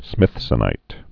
(smĭthsə-nīt)